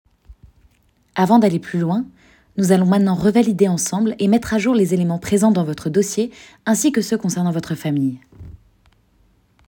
Essai voix